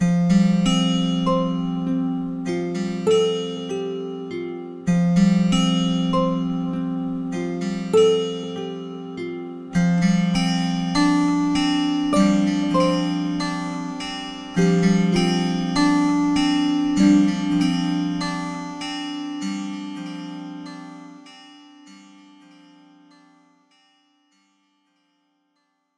Category 🎵 Relaxation